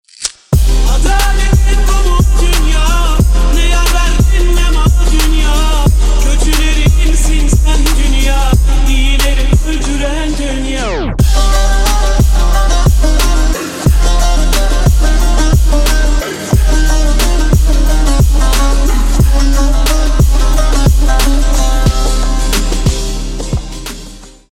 восточные мотивы
турецкие
Arabic trap